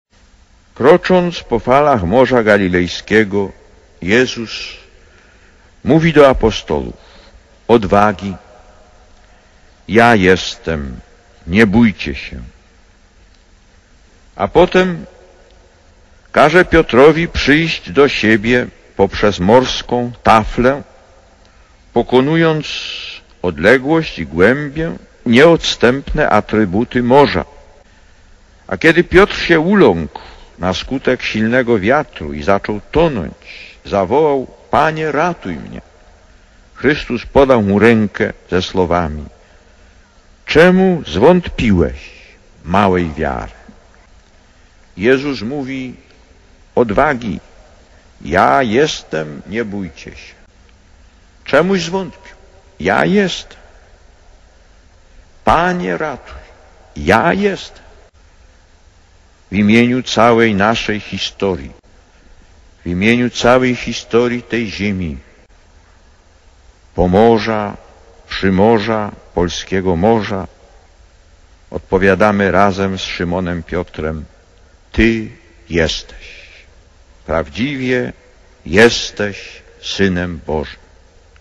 Lektor: Z homilii podczas Mszy św. skierowanej do ludzi morza (Gdynia 11 czerwca 1987 – nagranie): „Nad Morze Galilejskie, co prawda niewielkie pod względem obszaru, prowadzi nas też dzisiejsza Ewangelia.